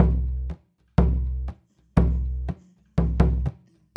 描述：传统桑巴乐器的循环播放
Tag: 循环 低音 桑巴